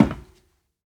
StepMetal4.ogg